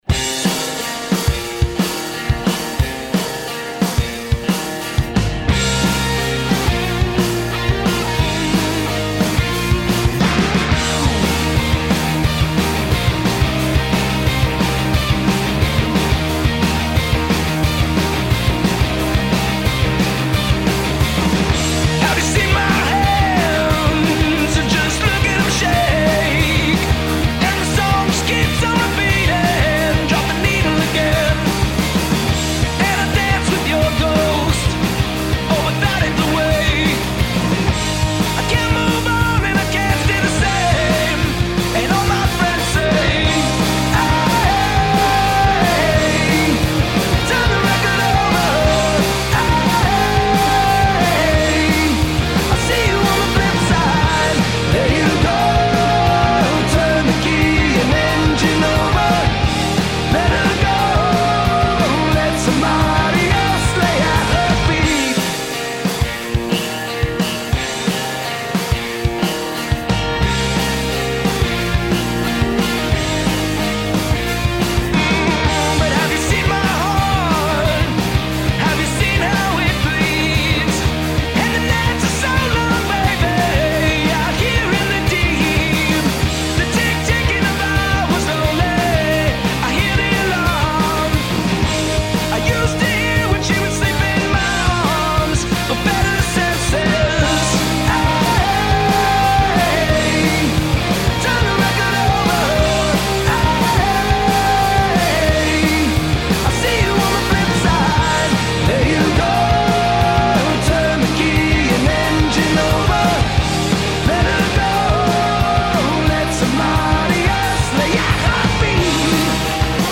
Perfect summer rock & roll.